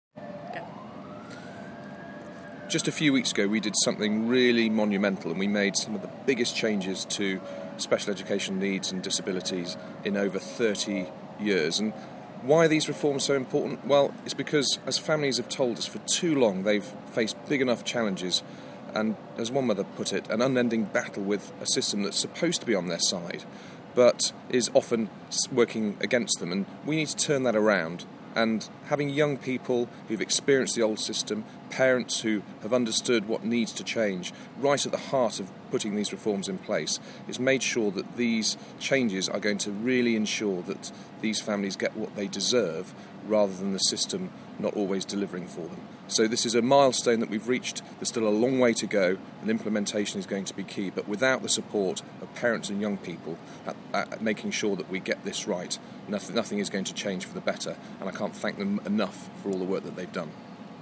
Minister Edward Timpson speaks at a reception thanking young adults for their help in shaping the reforms to special educational needs and disability policy. The Minister was speaking after a Lancaster House reception to toast those who took part on Thursday 25 September.